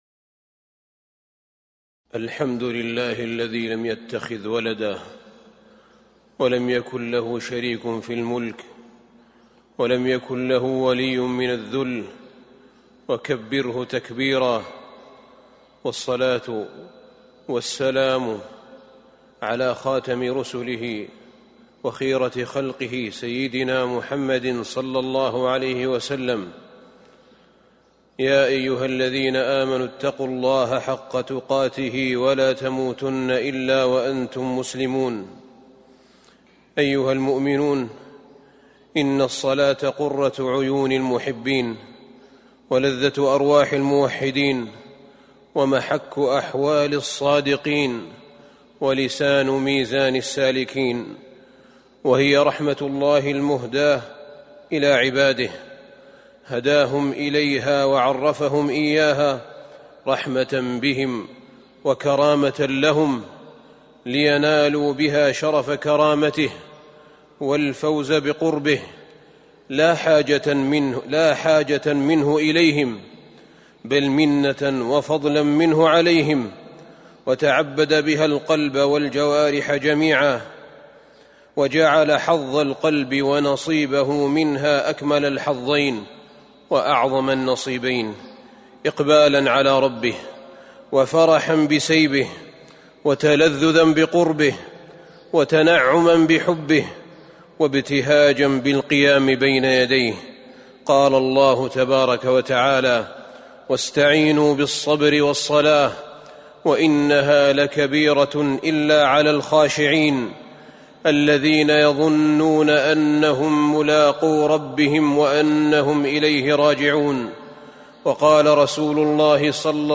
تاريخ النشر ٣ صفر ١٤٤٣ هـ المكان: المسجد النبوي الشيخ: فضيلة الشيخ أحمد بن طالب بن حميد فضيلة الشيخ أحمد بن طالب بن حميد ذوق الصلاة